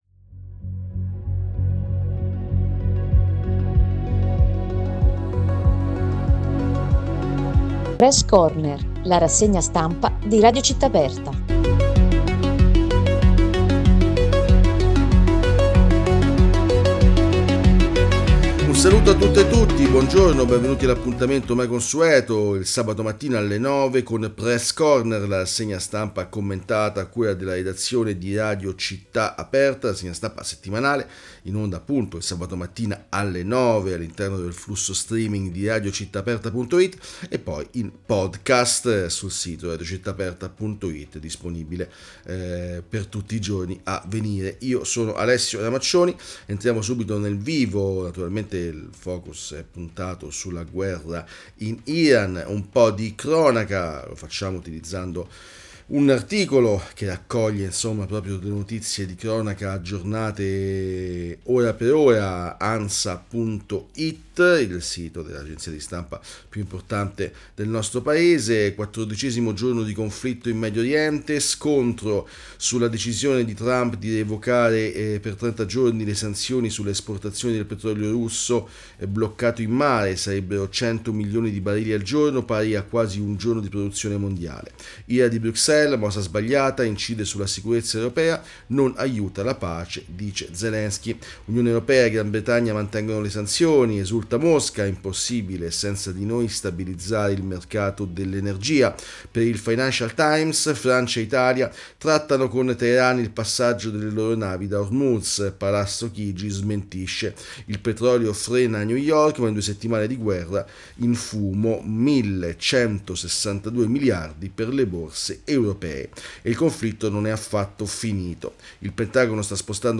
PRESS CORNER, la rassegna stampa settimanale di Radio Città Aperta – sabato 14 marzo 2026
Ogni sabato, in diretta alle 9 e poi in podcast, una selezione di notizie che raccontano i fatti più importanti della settimana.